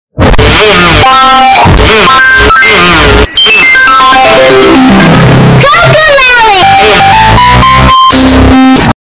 Ear Damage ( Loud Asf)